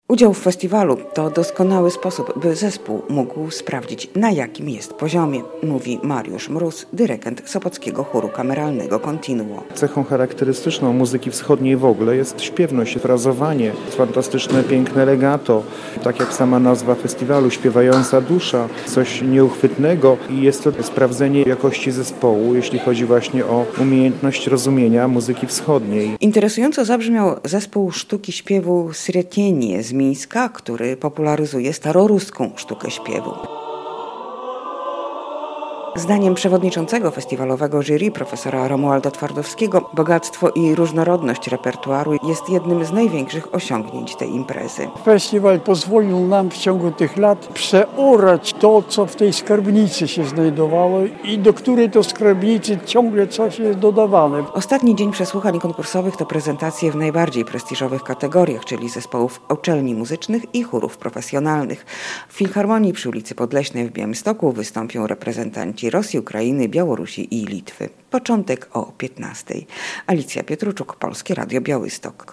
Studenci i wykładowcy wyższych uczelni to główni wykonawcy, którzy wystąpili podczas drugiego dnia przesłuchań konkursowych XXXVI Międzynarodowego Festiwalu Muzyki Cerkiewnej "Hajnówka 2017".